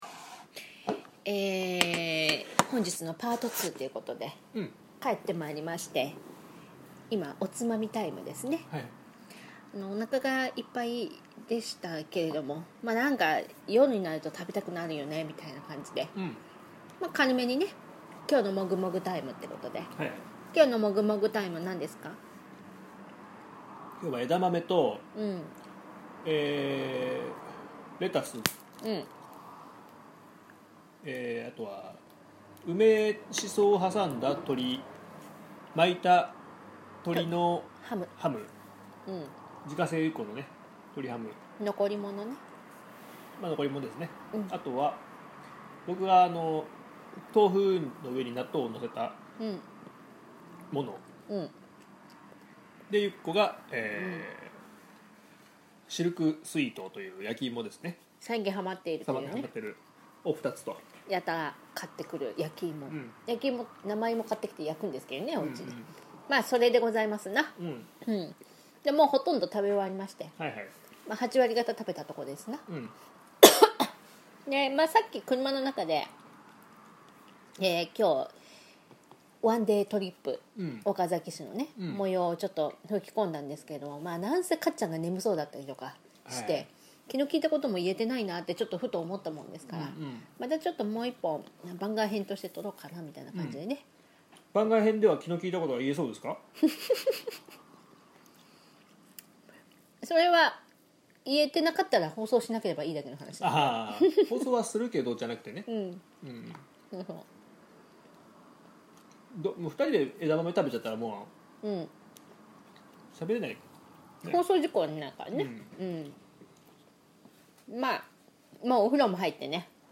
帰りの車中にて。